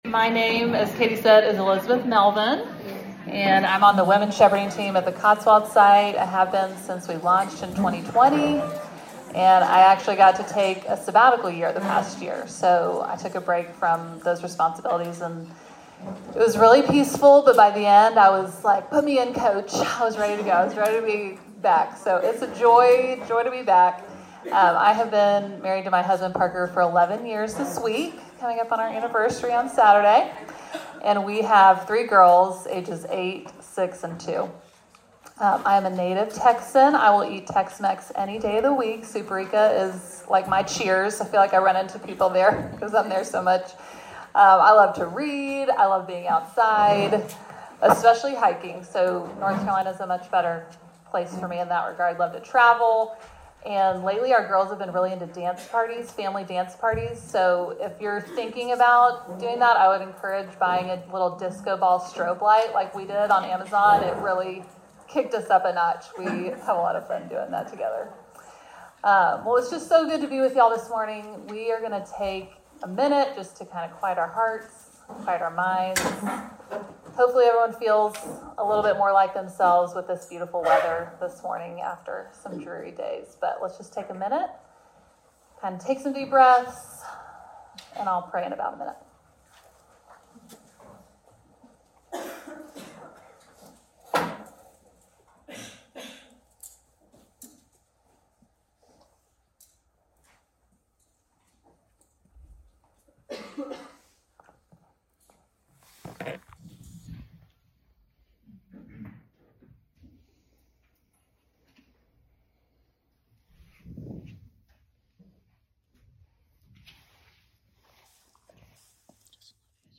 A message from the location "Women's Bible Study."